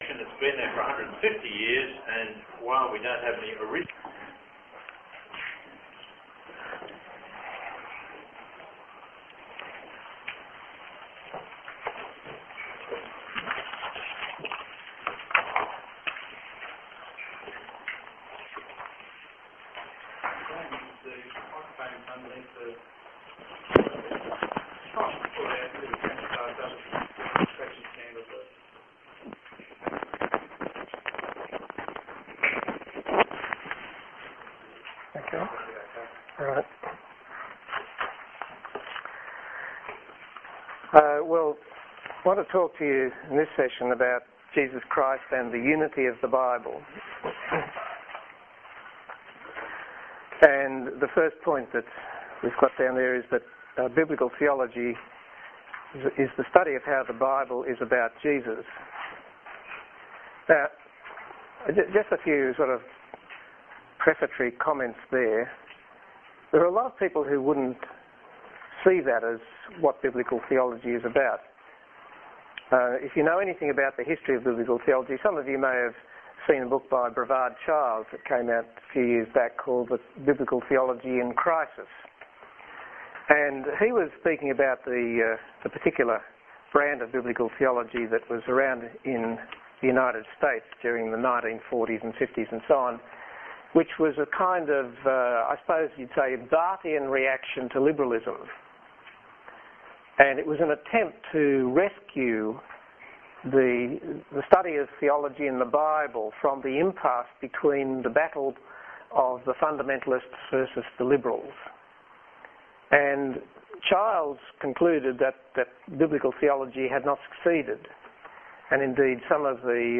A Practical Workshop on Preaching was held from 1-2 September 2004 at Islington Baptist Church 178 Maitland Road, Islington (opposite Islington Park)
Hunter Gospel Ministries Preaching Conference 2004